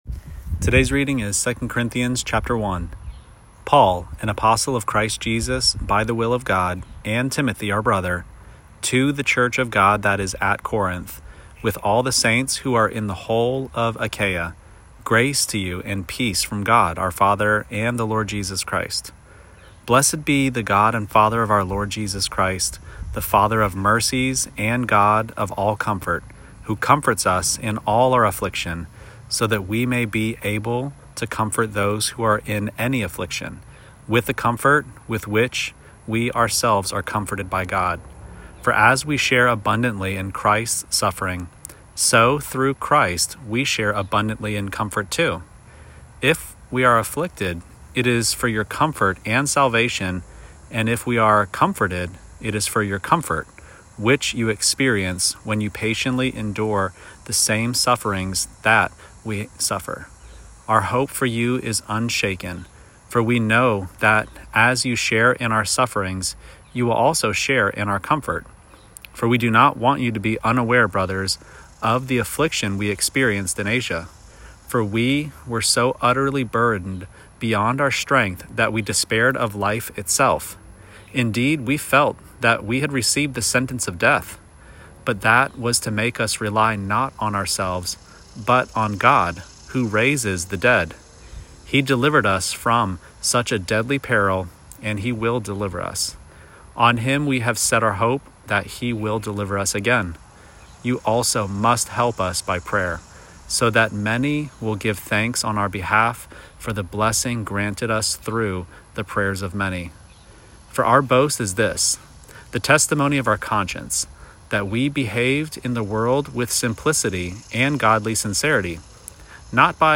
Daily Bible Reading (ESV)